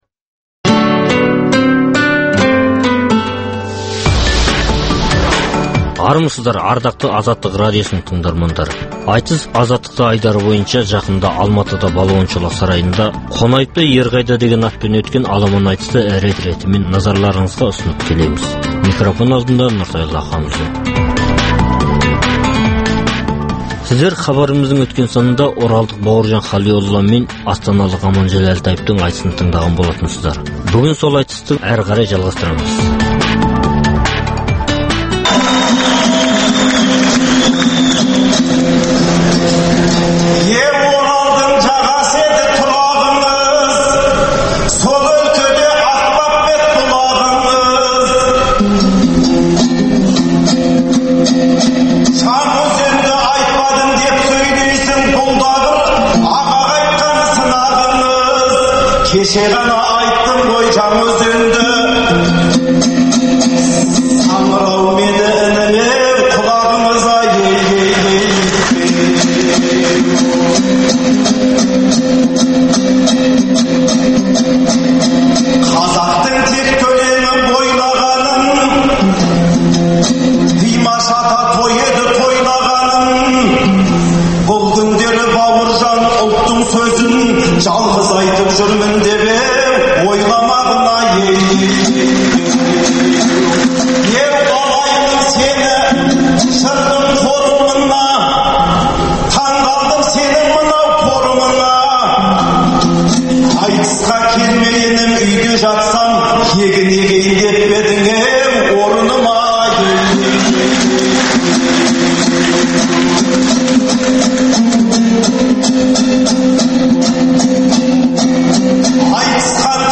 Балуан шолақ сарайындағы Қонаевтың құрметіне өткізілген айтыстағы мәрелік сайыстарын ұсынамыз.